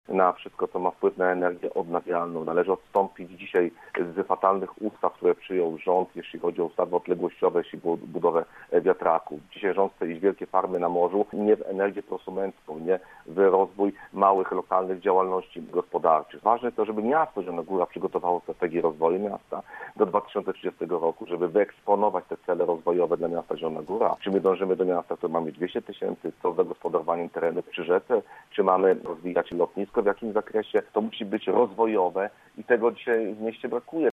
Pytanie to padło podczas dzisiejszej audycji Miejski Punkt Widzenia, a okazją do jego zadania był obchodzony wczoraj Dzień Europy.
W planowaniu inwestycji należy postawić na wsparcie firm po kryzysie cowidowym, ochronę zdrowia, innowacje i ochronę środowiska – uważa gość programu Marcin Pabierowski, przewodniczący klubu radnych KO: